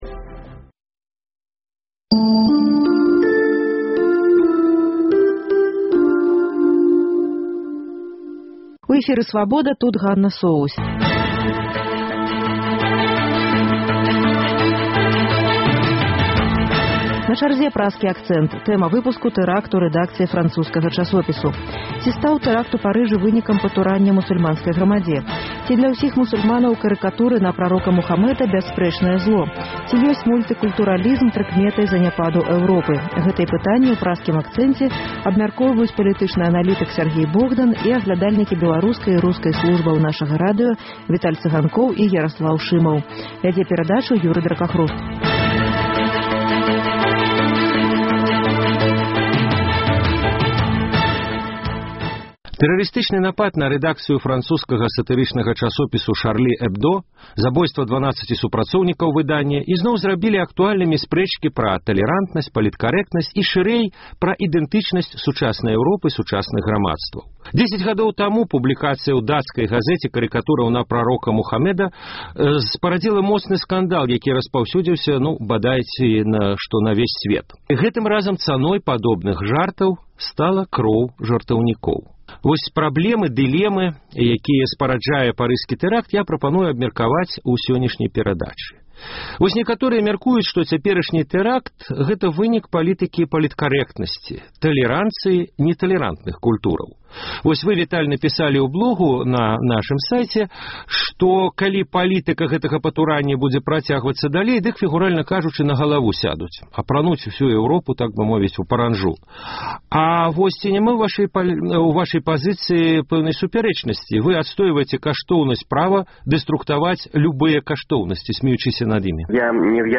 Ці ёсьць мультыкультуралізм прыкметай заняпаду Эўропы? Гэтыя пытаньні ў Праскім акцэнце абмяркоўваюць палітычны аналітык